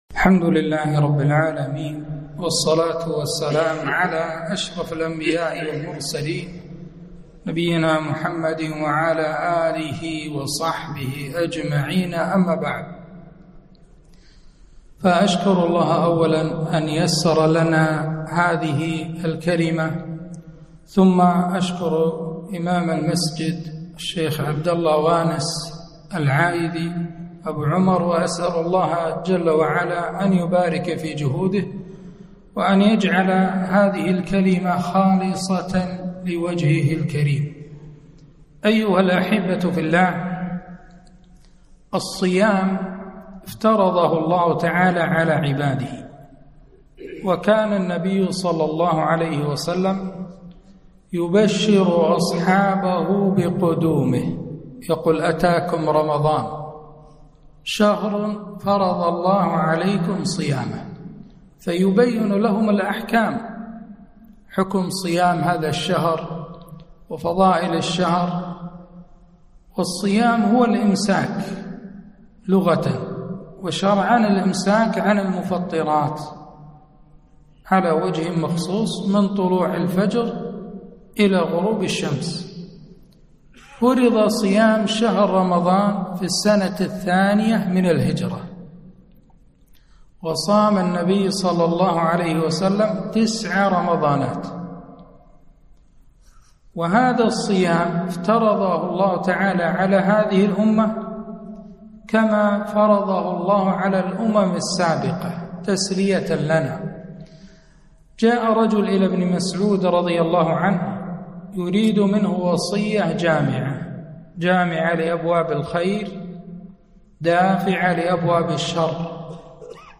محاضرة - رمضان فضائل وأحكام